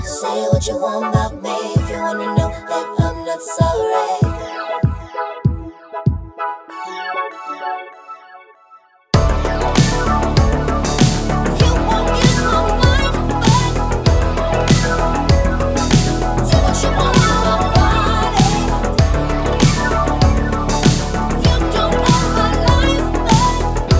no Backing Vocals Dance 3:54 Buy £1.50